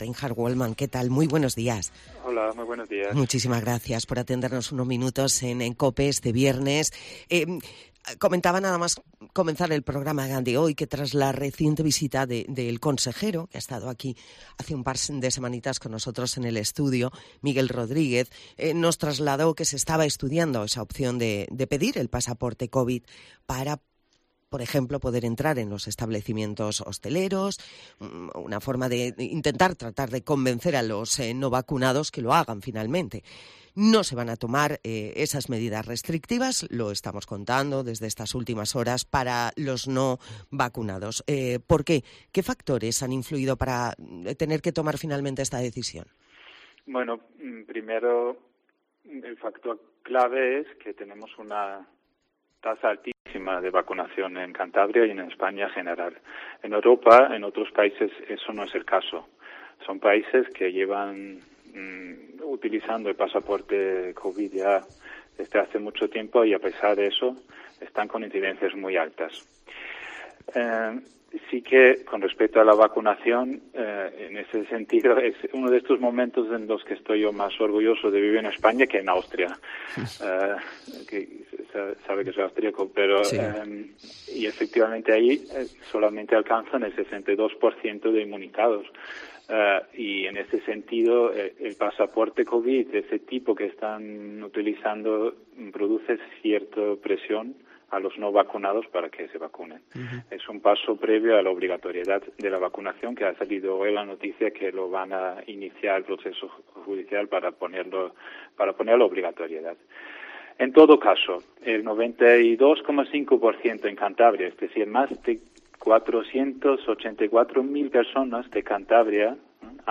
Entrevista en Cope a Reinhard Walmann, director de salud pública en Cantabria